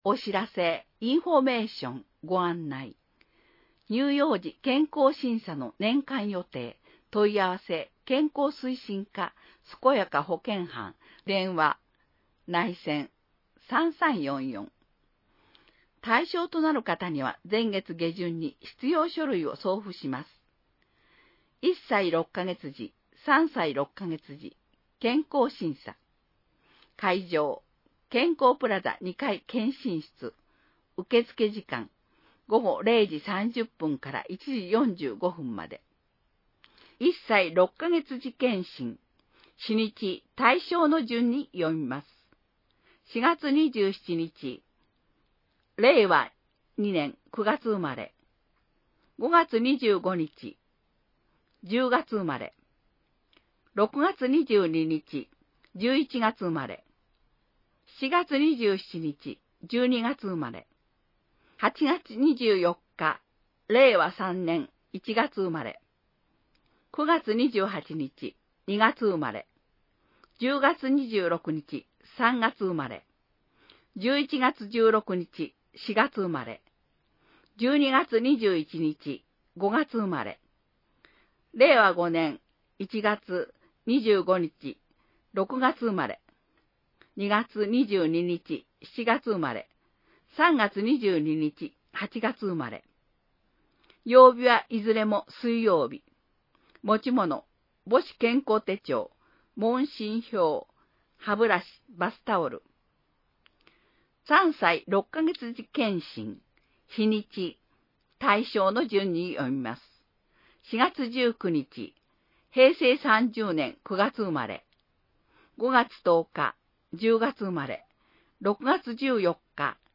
町の助成制度 (PDFファイル: 1.8MB) インフォメーション (PDFファイル: 3.1MB) あいかわカレンダー (PDFファイル: 1019.8KB) 音声版「広報あいかわ」 音声版「広報あいかわ」は、「愛川町録音ボランティアグループ かえでの会」の皆さんが、視覚障がい者の方々のために「広報あいかわ」を録音したものです。